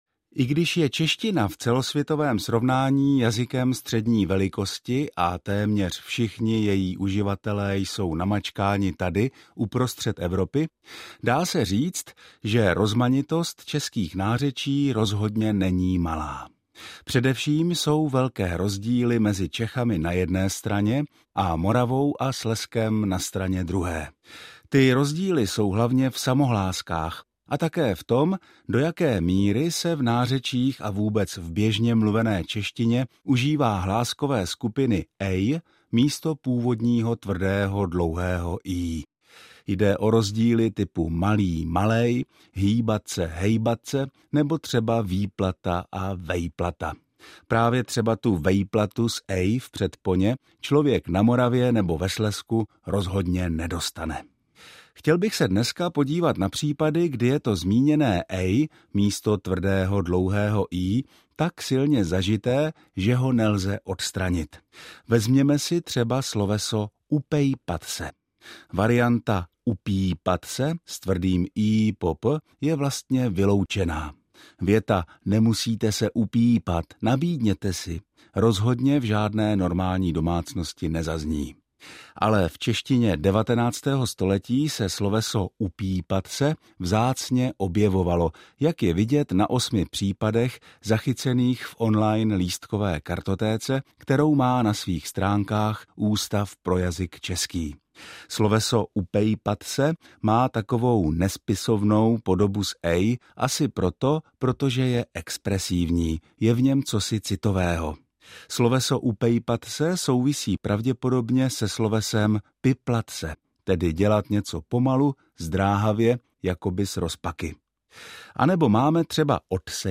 Zprávy ČRo Olomouc: Šok na tenisovém turnaji v Prostějově: Menšík končí už v prvním kole, vyřadil ho klubový parťák - 03.06.2025